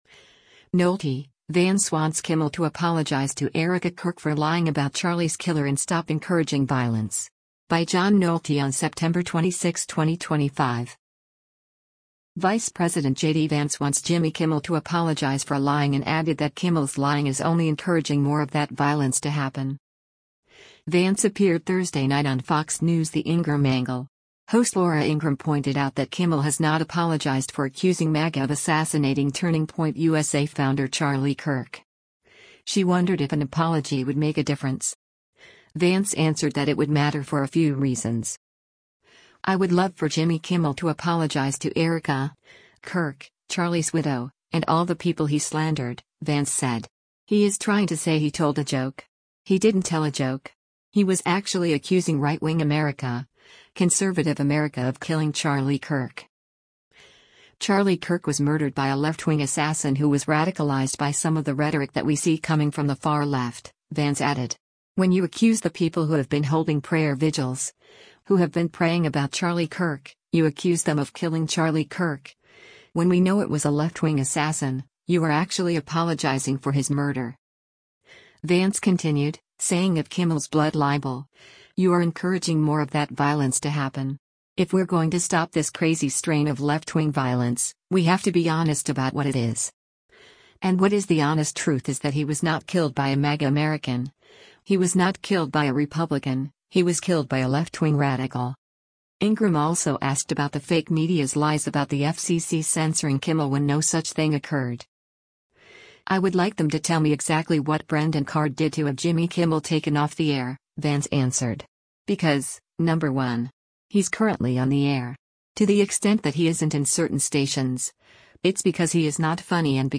Vance appeared Thursday night on Fox News’ The Ingraham Angle. Host Laura Ingraham pointed out that Kimmel has not apologized for accusing MAGA of assassinating Turning Point USA founder Charlie Kirk.